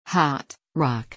HOT/hɑːt/, ROCK/rɑːk/
hot.mp3